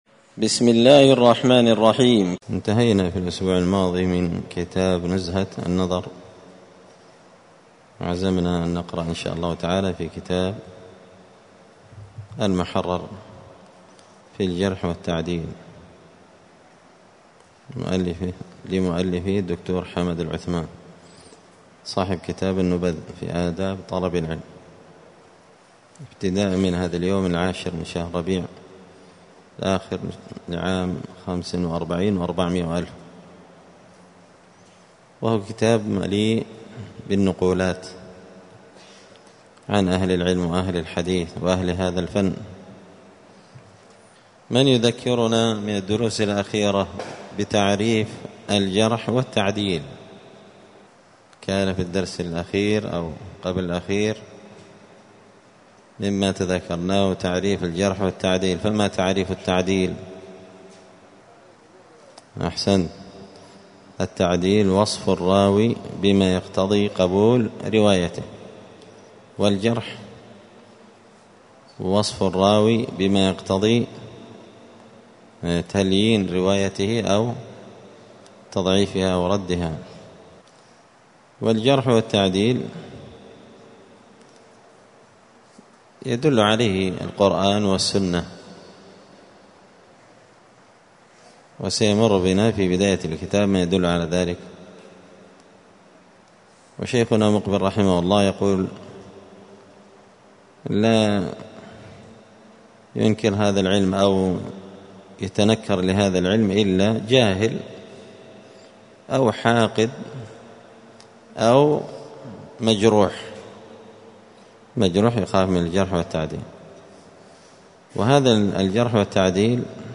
*الدرس الأول (1) تعريف الجرح والتعديل*